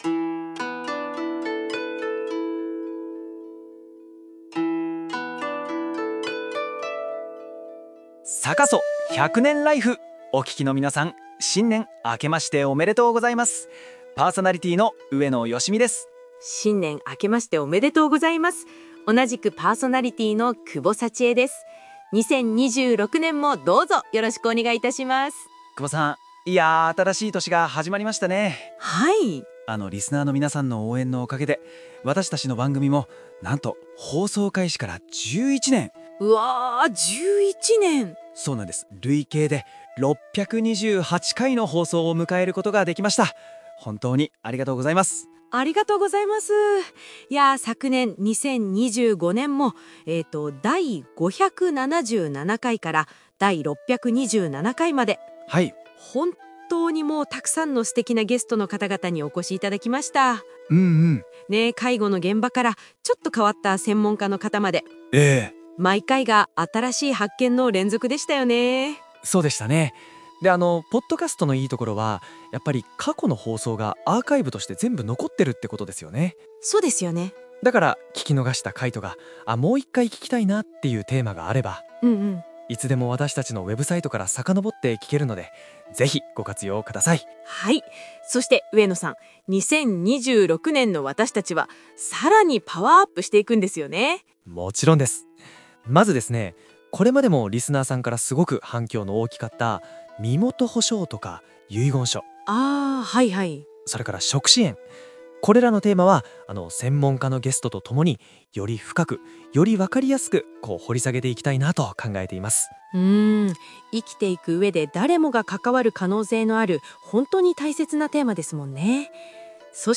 年末年始は自身の介護等、いろいろあって 今回は、今年やりたいことをＡＩに発表してもらうことにしました！